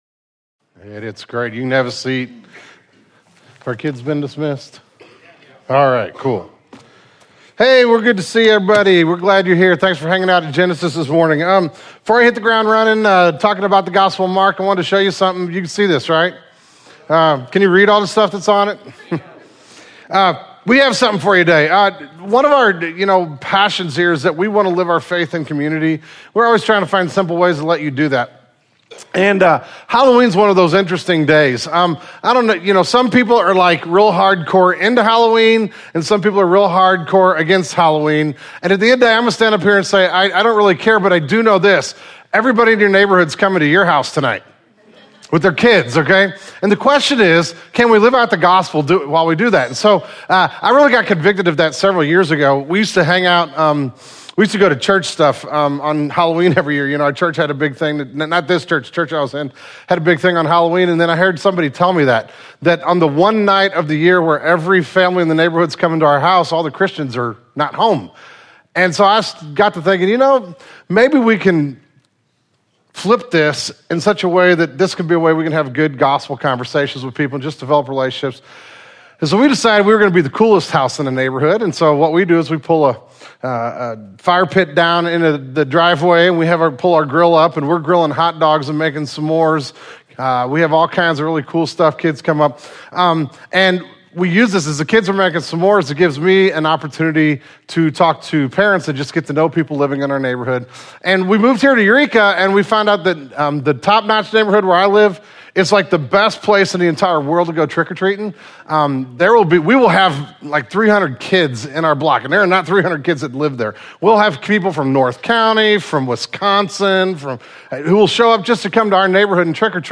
The key question for a disciple is not how to follow, but who to follow? In this sermon we will look at a simple question--Why should we follow Jesus?